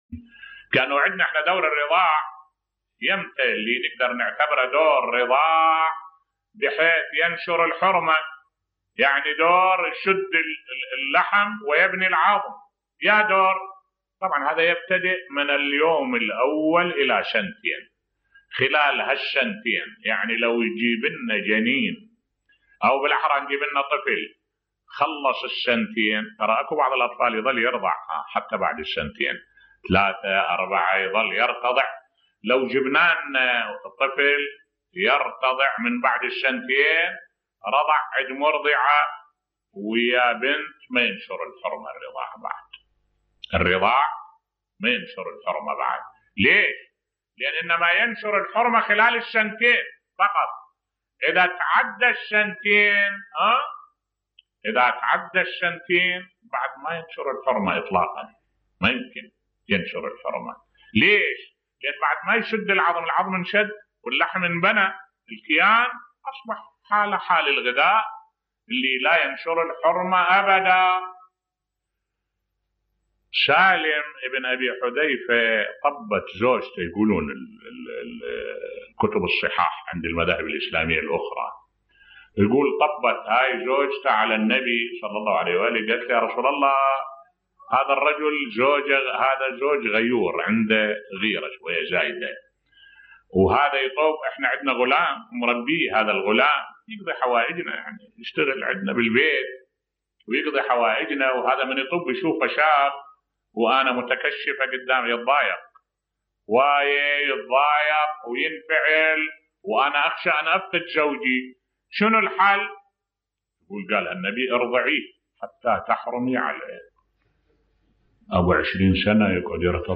ملف صوتی رضاع الكبير و سالم مولى أبي حذيفة بصوت الشيخ الدكتور أحمد الوائلي